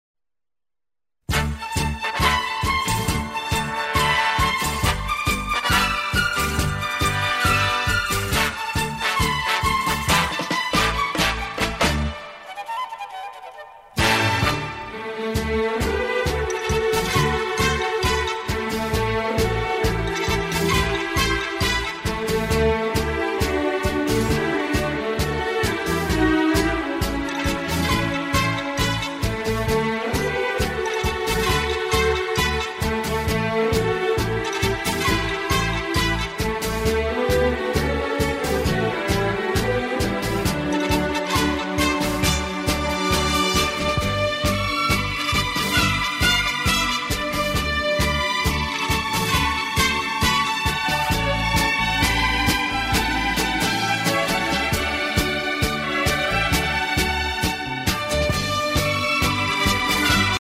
Super Instrumental